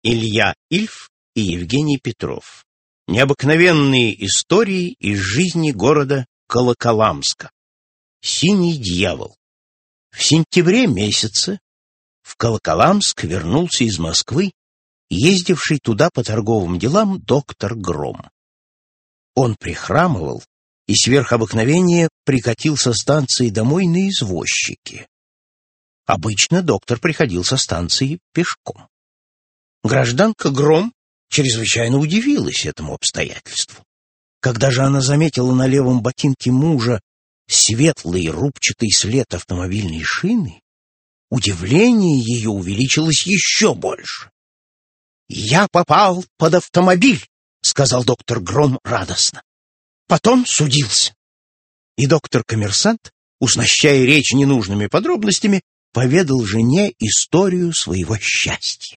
Аудиокнига Необыкновенные истории из жизни города Колоколамска | Библиотека аудиокниг